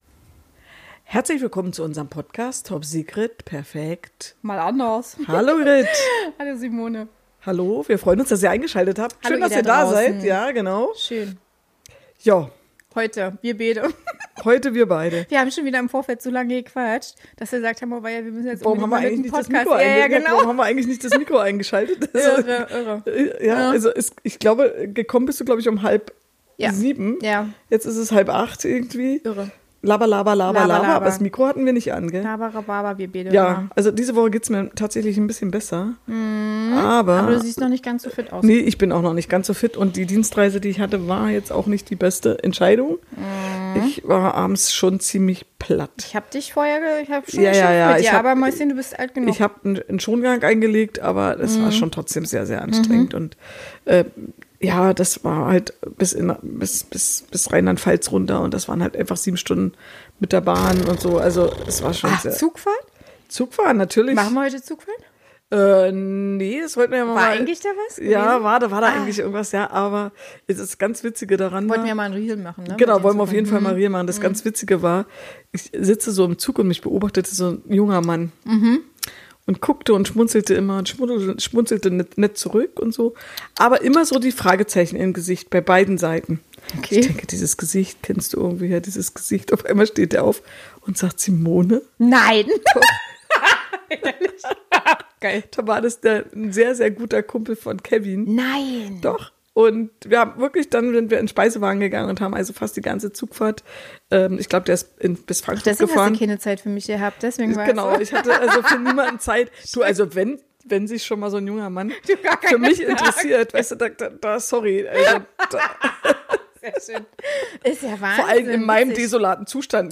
Wir stellen Thesen oder Behauptungen auf und diskutieren sie humorvoll. Sind unsere Thesen wahr oder sind sie einfach nur komplett falsch.